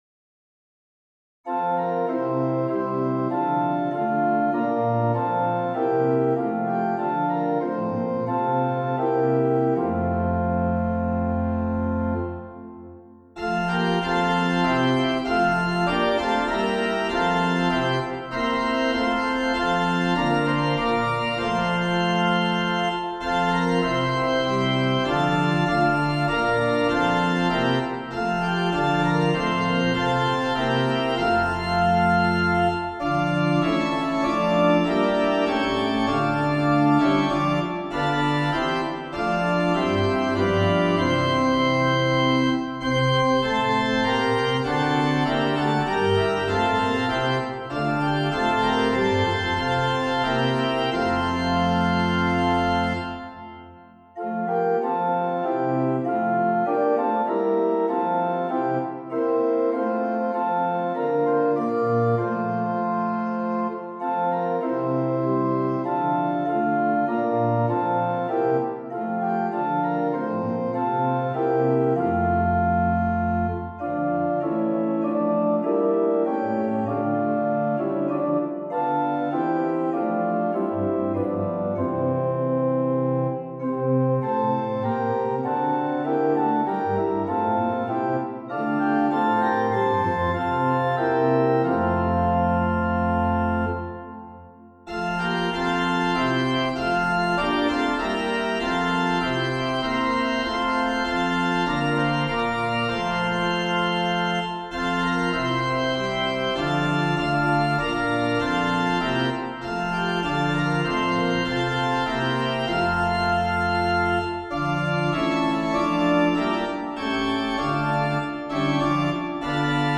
Traditional English